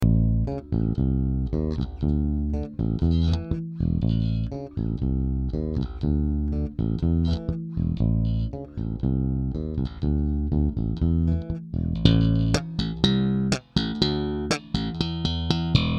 ExEQ
Edit: teď jak to slyšim bez těch kytar a bicích tak je sakra znát jak moc musim zamakat na přesnosti, úděsně to kulhá ...